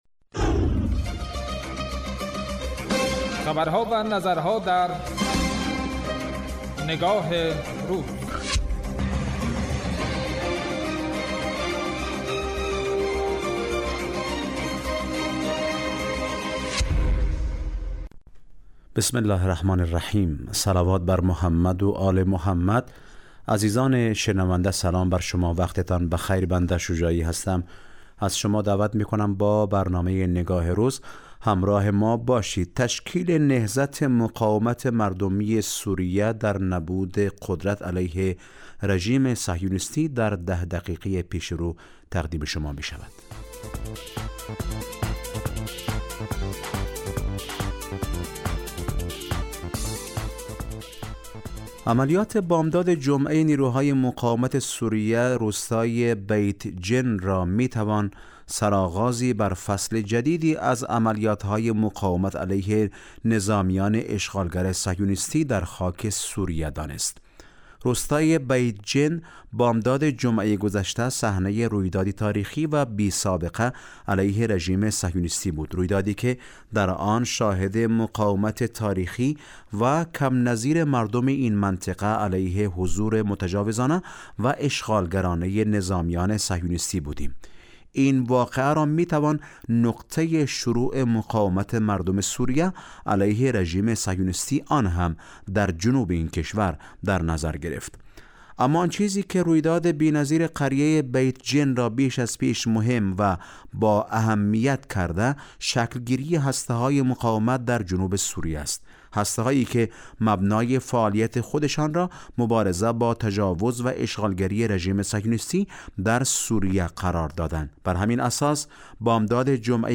این واقعه را می‌توان نقطه شروع مقاومت مردم سوریه علیه رژیم صهیونیستی آن هم در جنوب این کشور درنظر گرفت. برنامه تحلیلی نگاه روز از شنبه تا پنجشنبه راس ساعت 14 به وقت کابل به مدت 10 دقیقه پخش می گردد